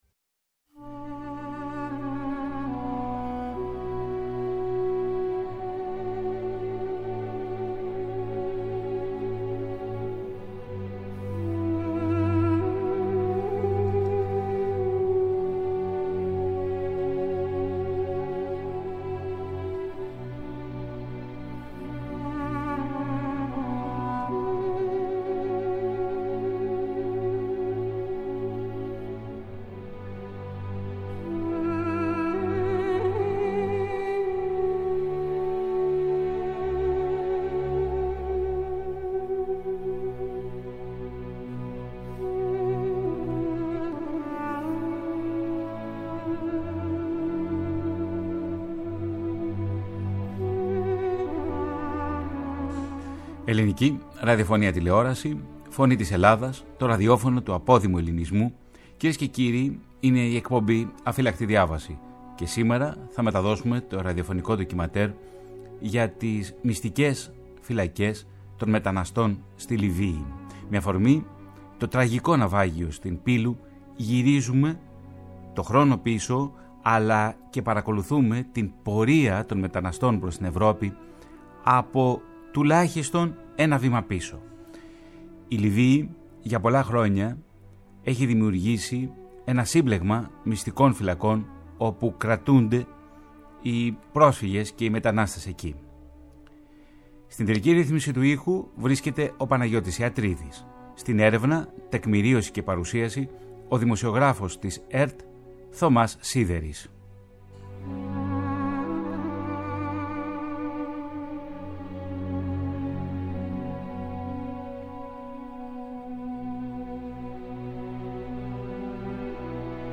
Με αφορμή την τραγωδία στην Πύλο, με τους δεκάδες νεκρούς και τους εκατοντάδες αγνοούμενους μετανάστες και πρόσφυγες, η εκπομπή “Αφύλαχτη Διάβαση” παρουσιάζει σήμερα Παρασκευή 23 Ιουνίου, ένα συγκλονιστικό ραδιοφωνικό ντοκιμαντέρ για τα κέντρα κράτησης μεταναστών στη Λιβύη, ιχνηλατώντας τις μεταναστευτικές ροές προς την Ευρώπη.